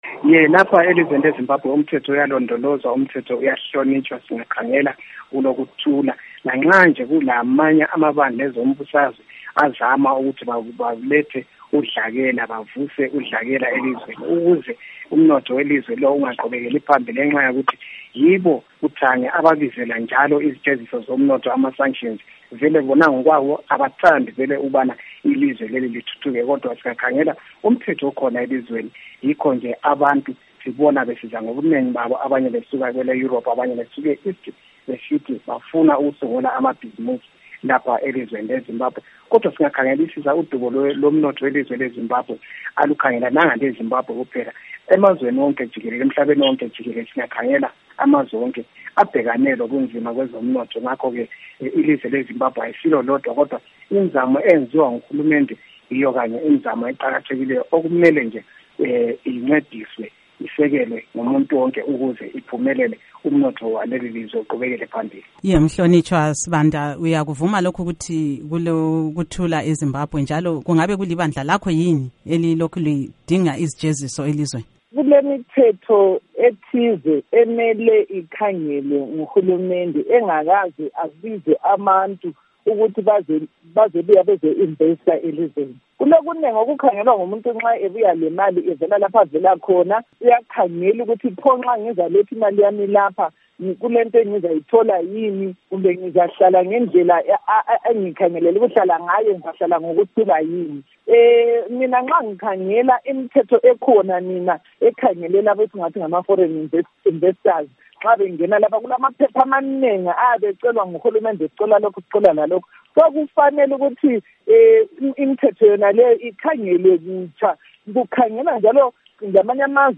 Ingxoxo mpikiswano